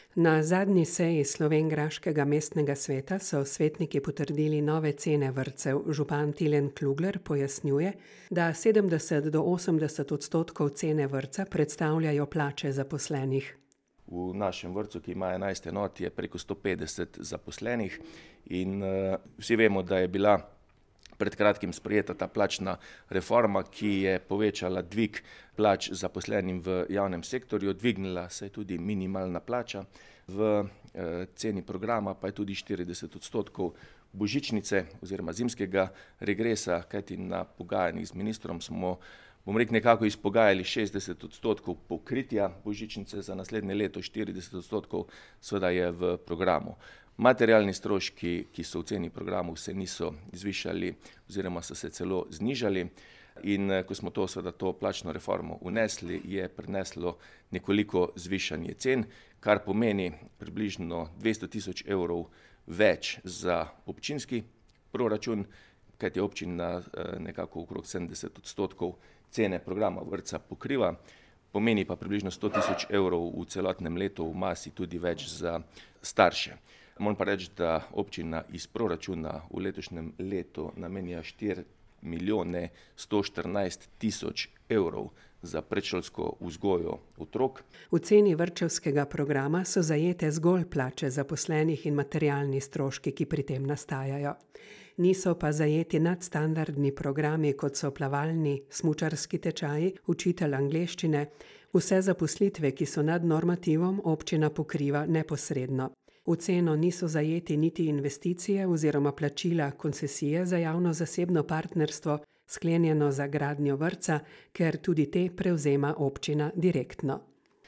Lokalne novice | Koroški radio - ritem Koroške